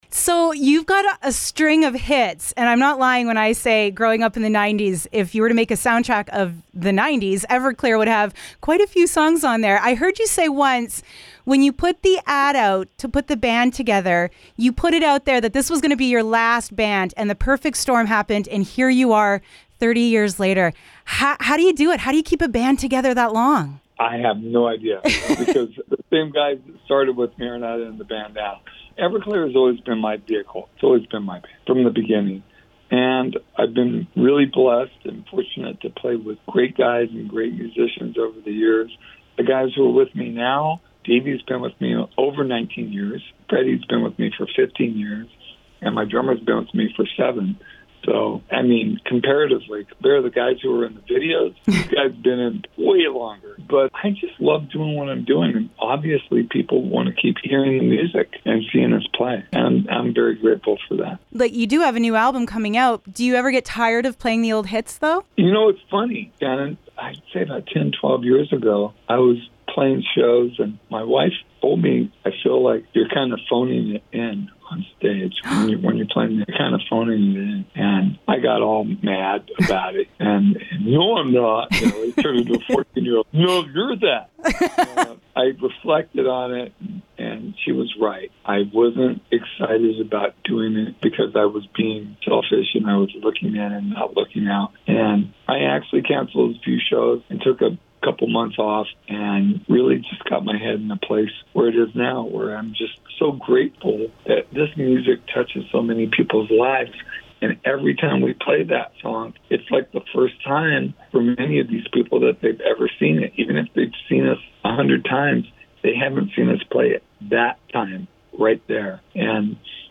Everclear’s frontman Art Alexakis joined us to talk about Everclear 30 years later and the new crop of fans: